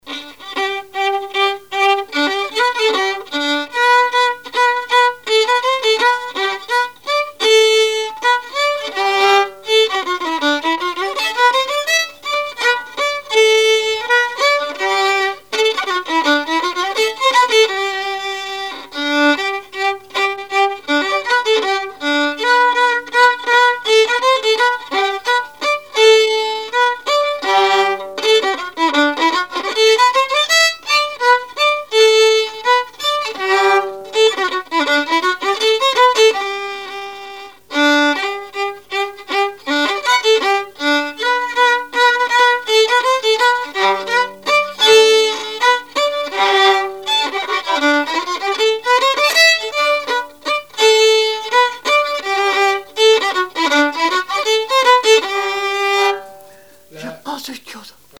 Saint-Christophe-du-Ligneron
Chants brefs - A danser
scottich sept pas
Répertoire musical au violon
Pièce musicale inédite